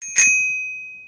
question_markTermékkör Ding-Dong csengő